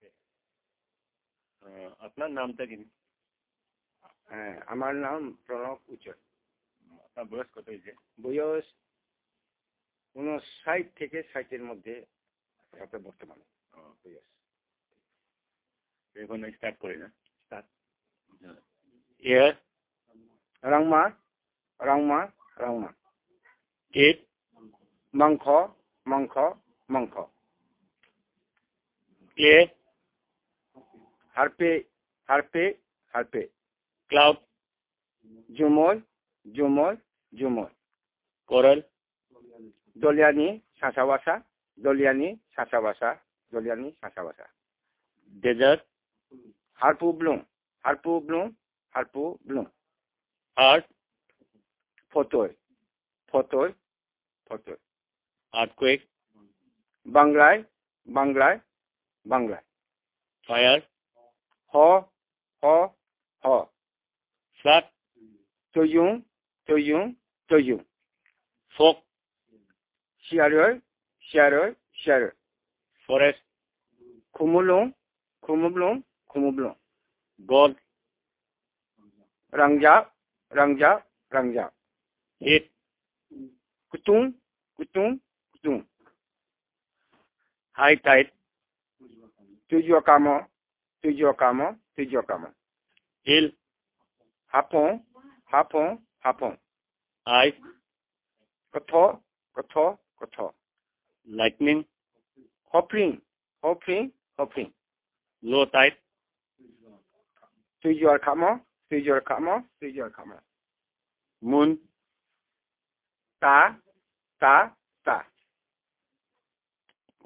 dc.description.elicitationmethodInterview method
dc.type.discoursetypeWord list elicitation